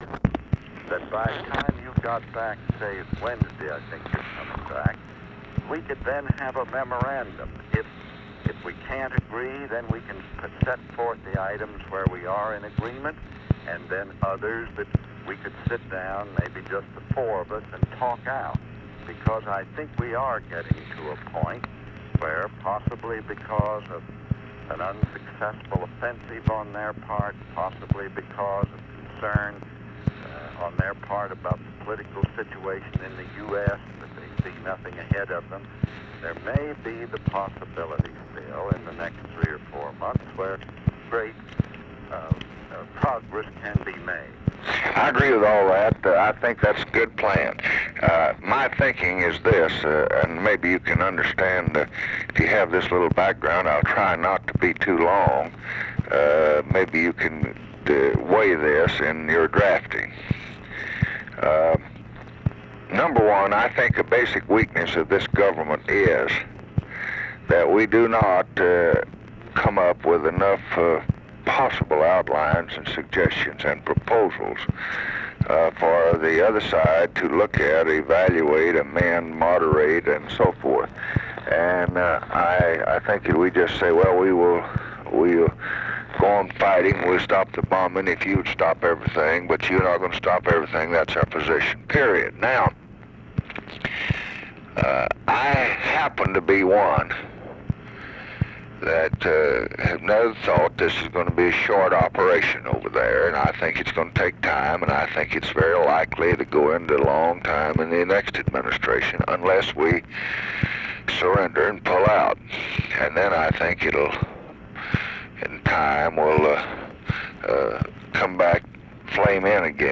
Conversation with CLARK CLIFFORD, September 2, 1968